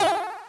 jump1.ogg